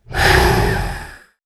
mixkit-aggressive-beast-roar-13.wav